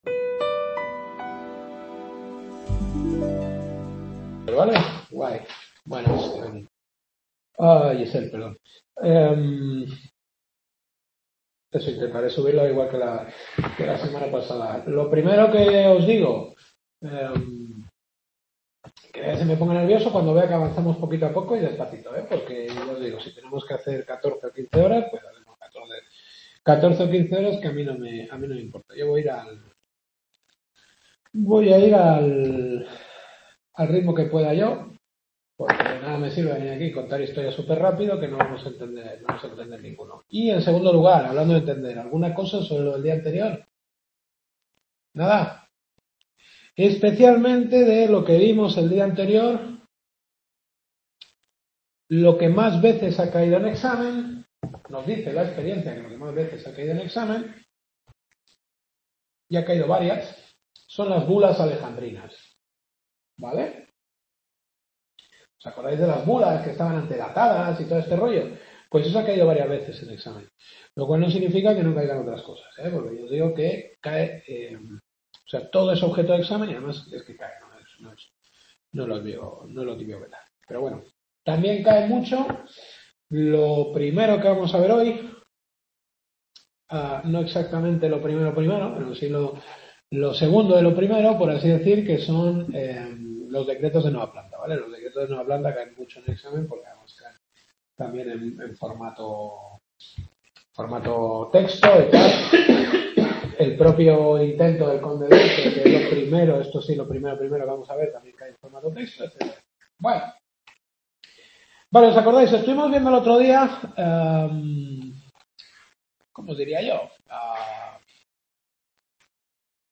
Segunda clase.